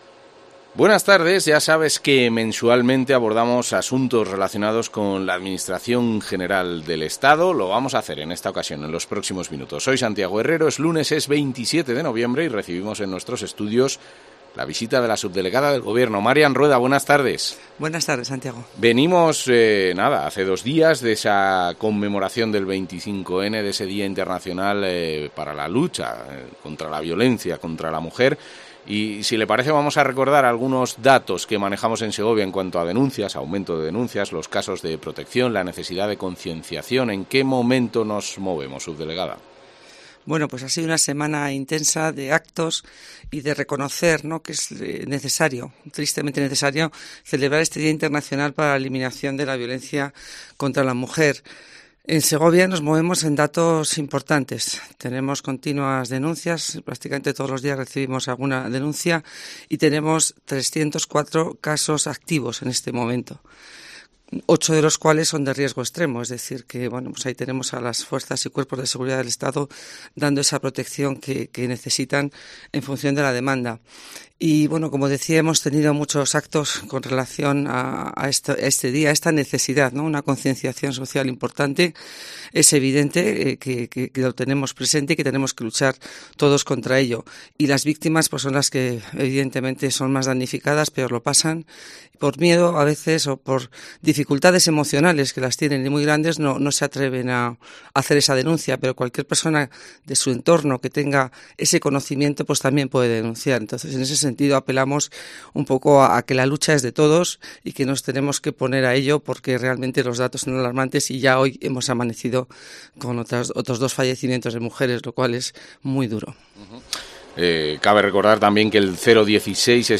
Marian Rueda, subdelegada del Gobierno en Segovia